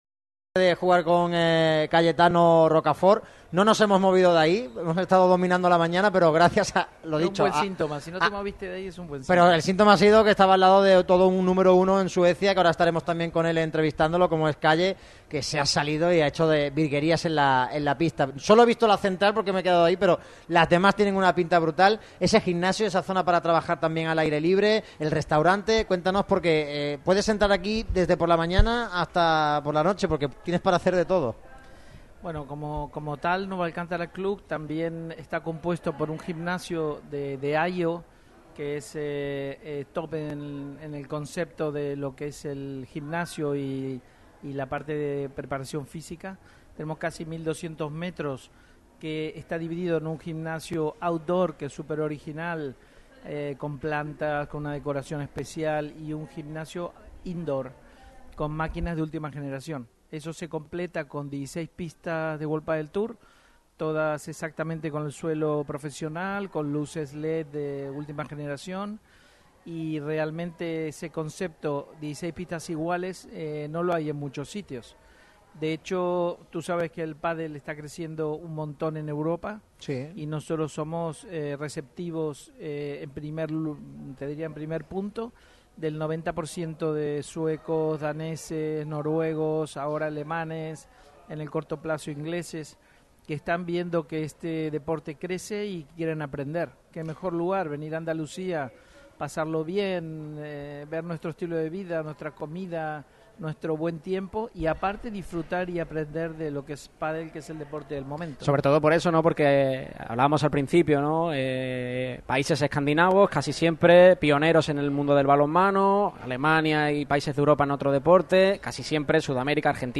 Radio MARCA Málaga ha celebrado su habitual programa de viernes en las instalaciones del Nueva Alcántara Club en San Pedro.